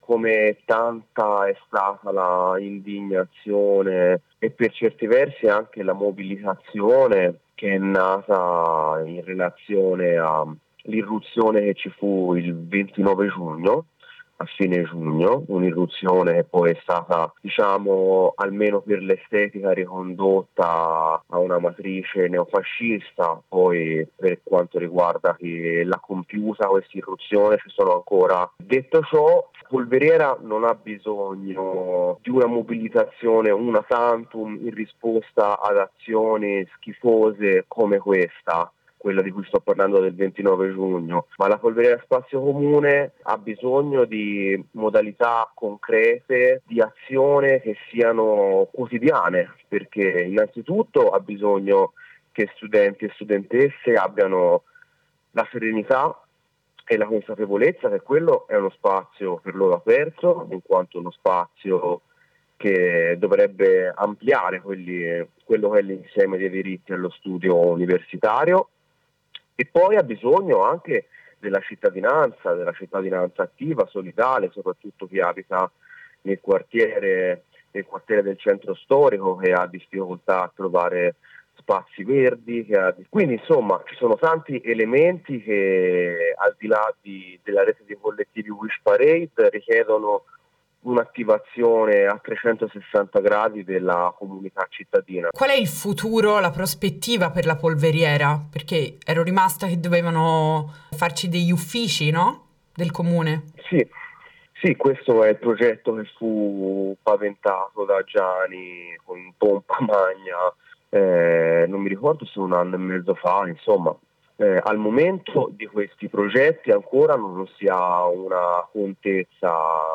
Audio: Intervista a un membro di Wishparade Condividiamo il comunicato stampa diffuso da Wishparade in seguito al Presidio Sonoro dello scorso 19 luglio: “Dopo l’ultima Street Parade, il 19 luglio 2025 si è tenuto il quarto Presidio Sonoro della rete di collettivi uniti Wish Parade nel chiostro di Sant’Apollonia, in sostegno alla resistenza de La Polveriera Spazio Comune.